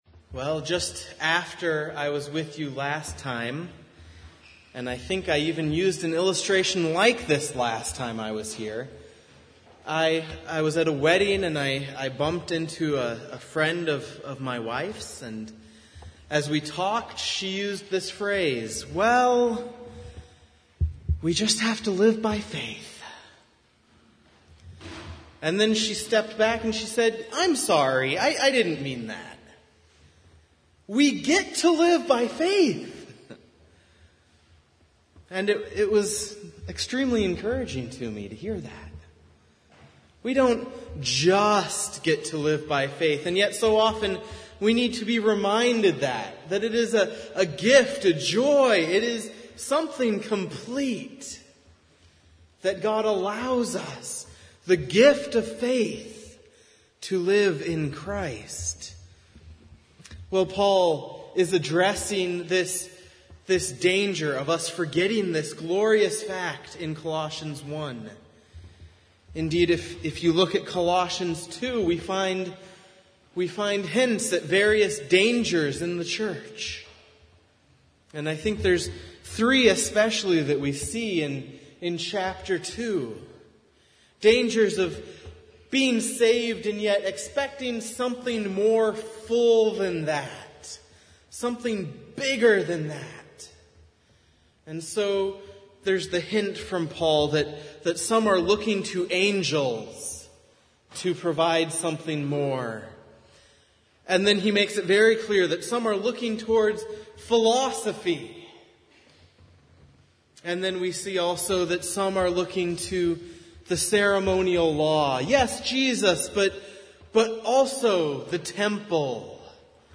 Series: Guest Preacher Passage: Colossians 1:21-23, Psalm 32:1-11 Service Type: Sunday Morning